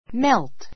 melt mélt メ るト 動詞 溶 と ける, （心が） 和 やわ らぐ; 溶かす, （心を） 和らげる melt away melt away 溶けてなくなる, 消え去る The candy melted in my mouth.